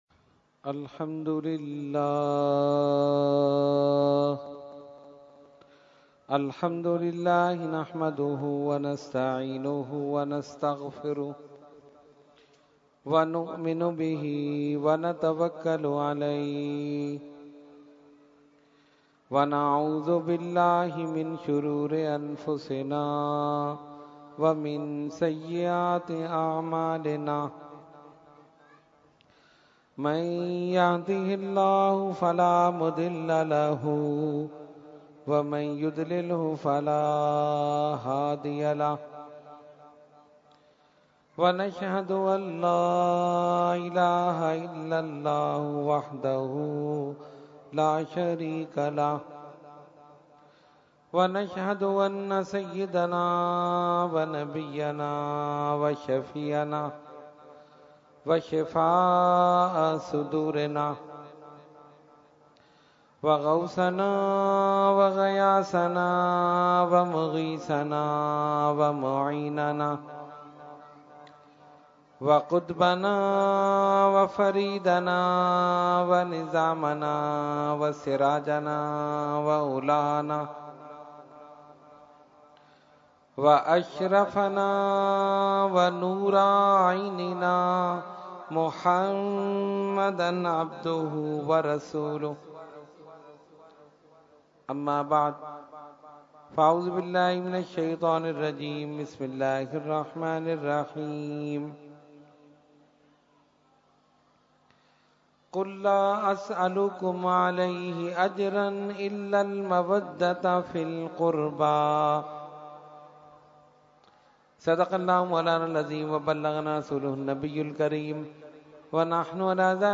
Category : Speech | Language : UrduEvent : Muharram 2016